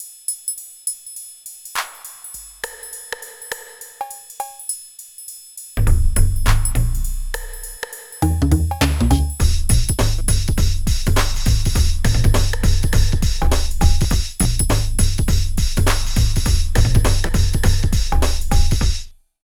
113 LOOP  -L.wav